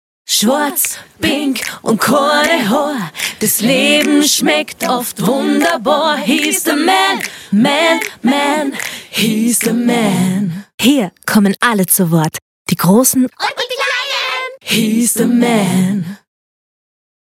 produziert in der Singstube im schönen Vorarlberg.